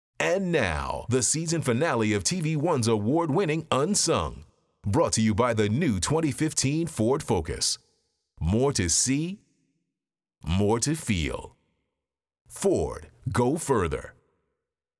FORD_UNSUNG_Finale_v03_VO.wav